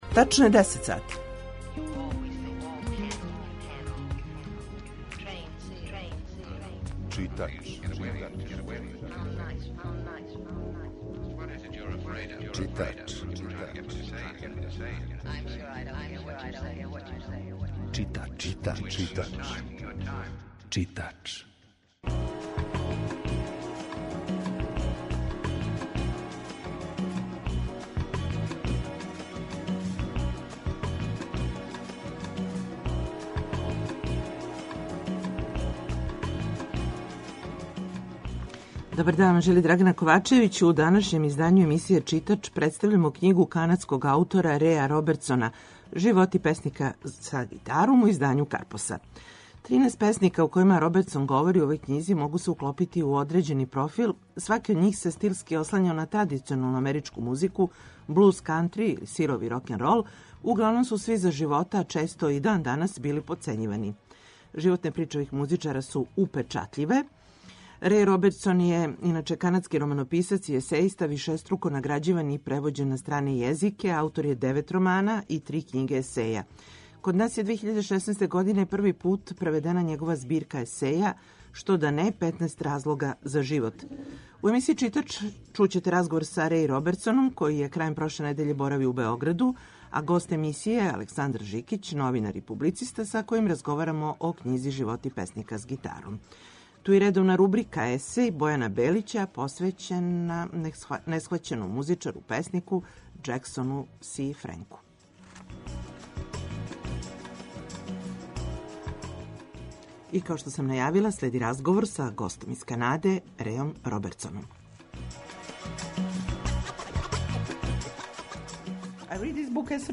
У емисији Читач чућете разговор са Реј Робертсоном који је прошле године боравио у Београду.